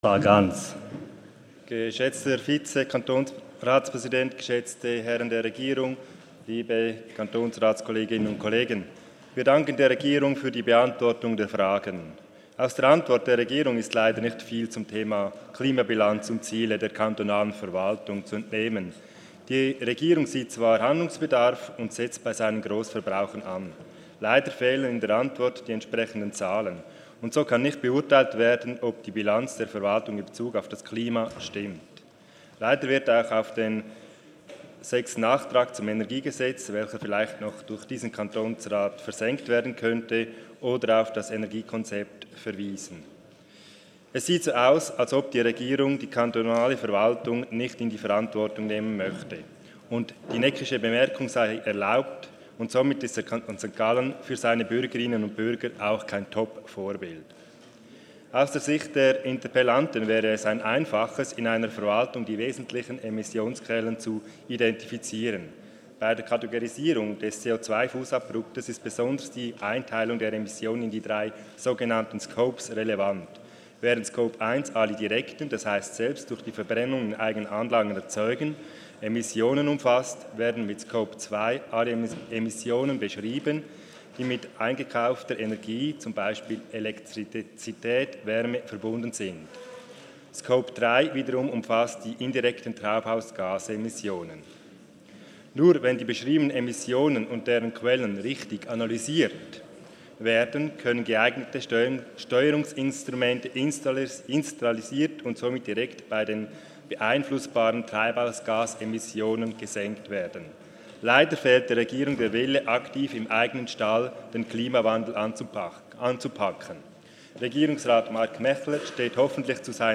25.11.2019Wortmeldung
Sprecher: Tanner-Sargans
Session des Kantonsrates vom 25. bis 27. November 2019